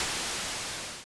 wizard_attack.wav